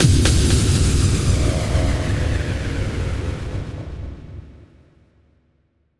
Impact 17.wav